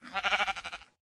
sheep1